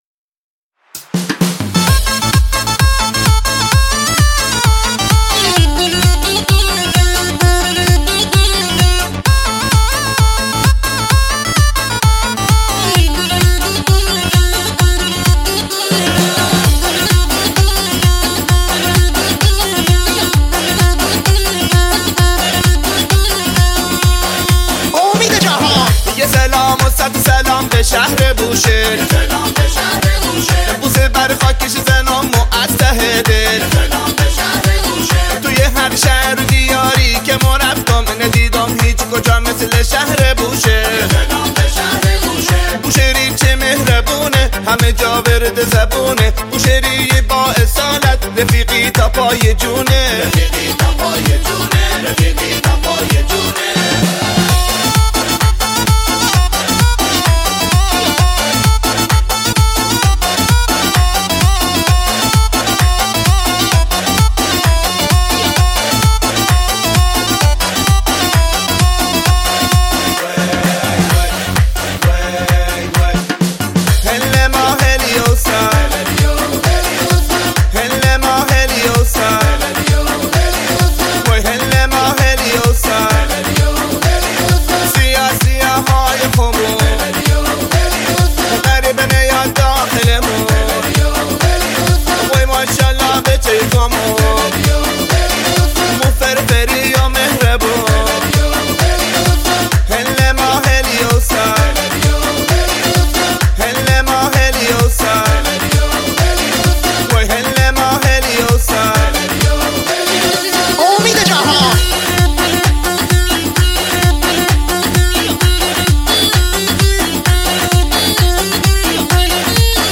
آهنگ شاد
تک اهنگ ایرانی